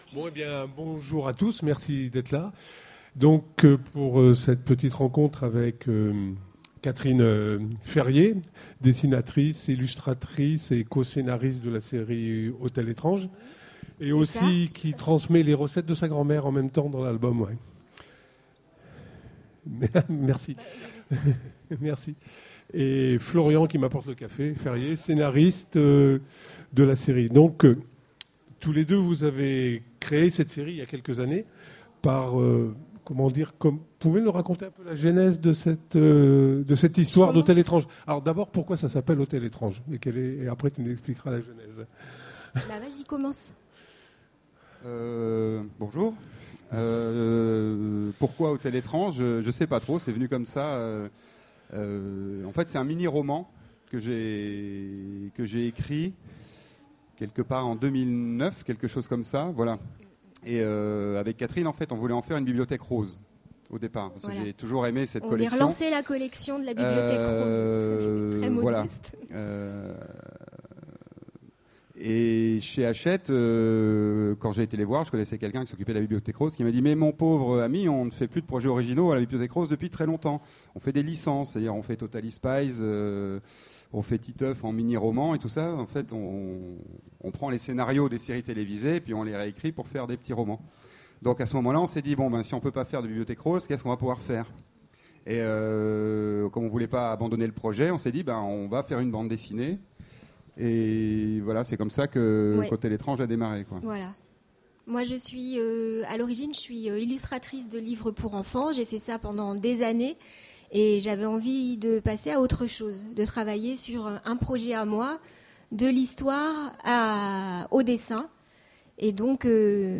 Utopiales 13 : Conférence Bienvenue à l'hôtel étrange